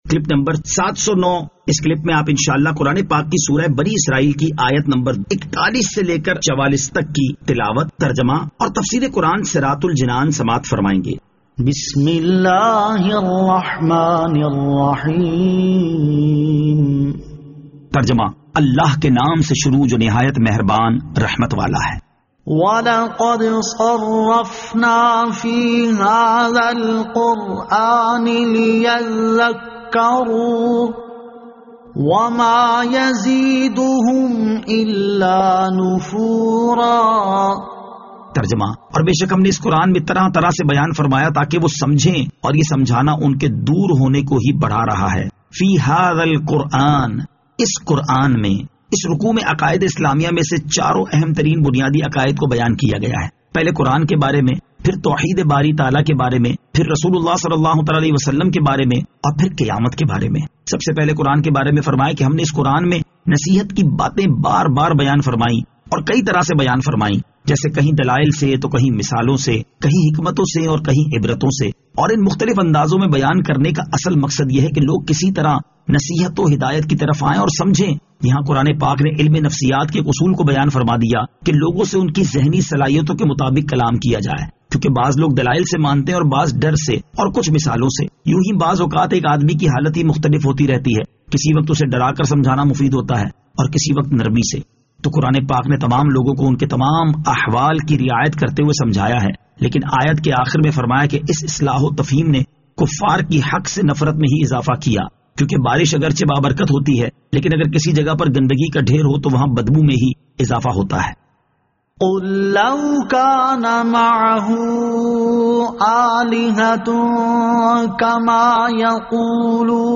Surah Al-Isra Ayat 41 To 44 Tilawat , Tarjama , Tafseer